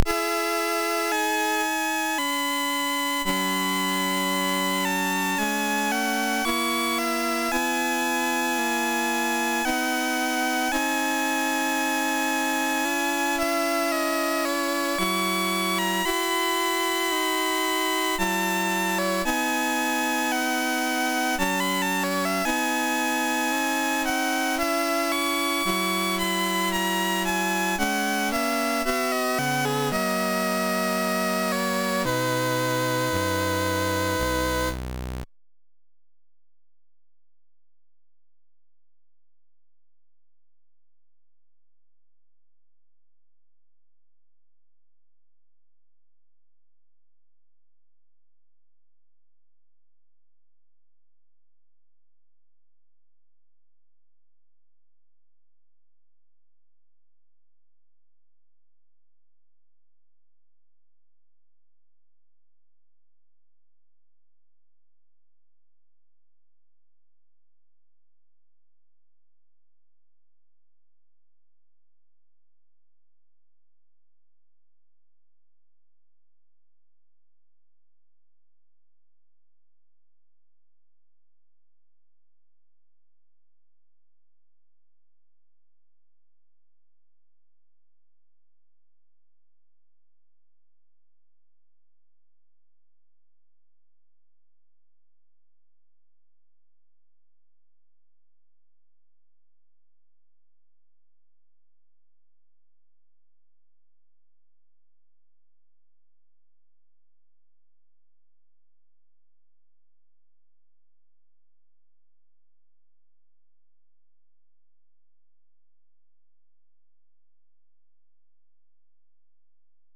home *** CD-ROM | disk | FTP | other *** search / Loadstar 135 / 135.d81 / intermezzo.mus ( .mp3 ) < prev next > Commodore SID Music File | 2022-08-26 | 431b | 1 channel | 44,100 sample rate | 3 minutes